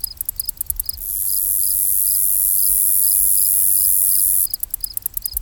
CICADA CR05R.wav